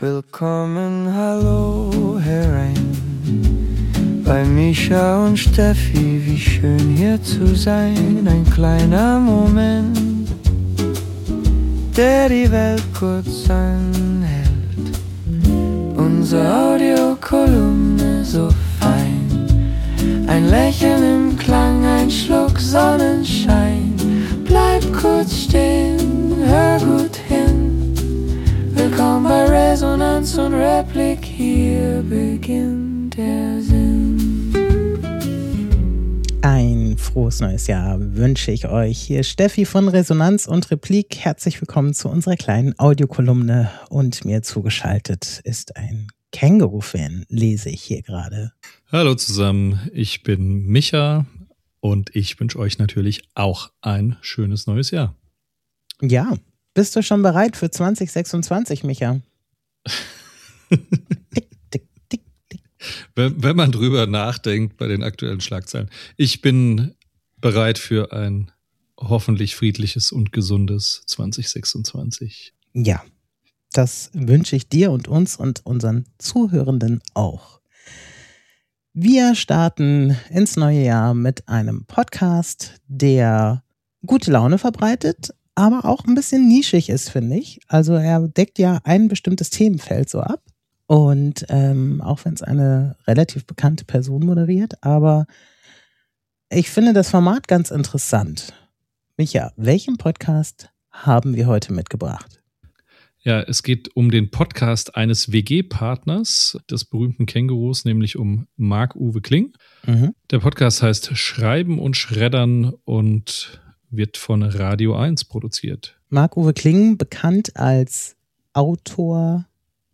Ein weiterer spannender Aspekt ist die Diskussion über die Herausforderungen der Filmproduktion in Deutschland, die die beiden Hosts humorvoll und kritisch beleuchten.